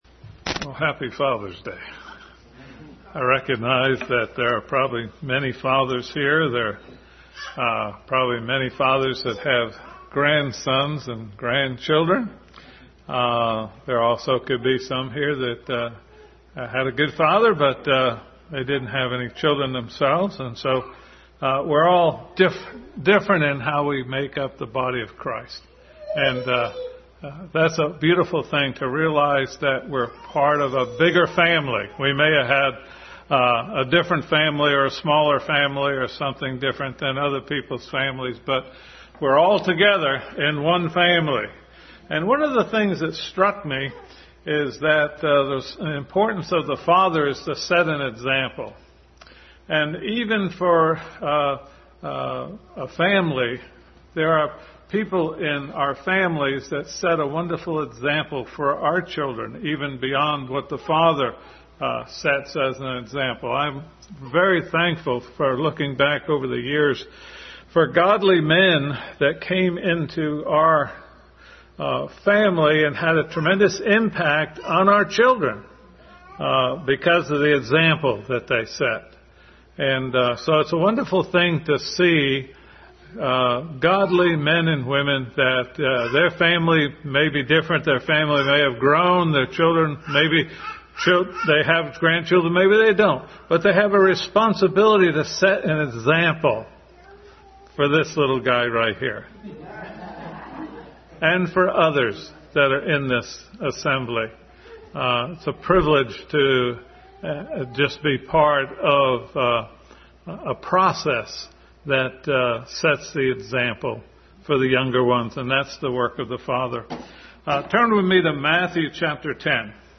Adult Sunday School Class.